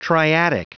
Prononciation du mot triadic en anglais (fichier audio)
Prononciation du mot : triadic
triadic.wav